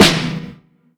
lIVEbS_SNR.wav